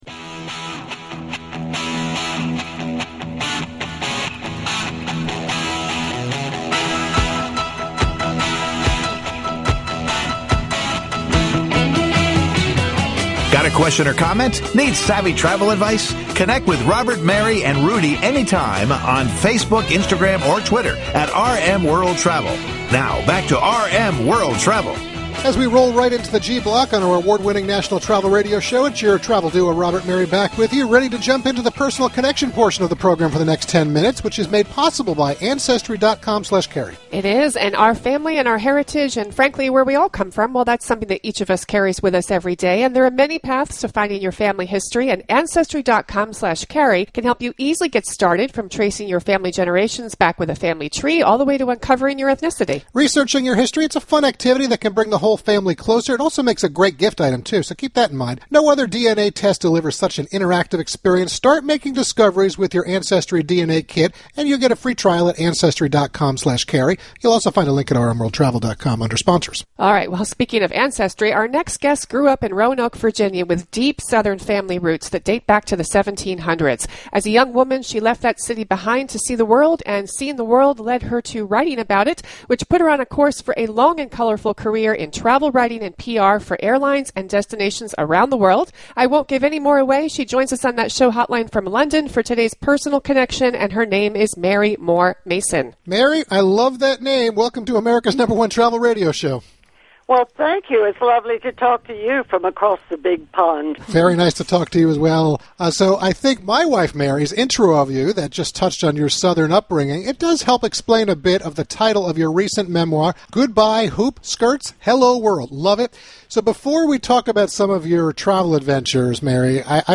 You can hear the full interview again now that aired during the live broadcast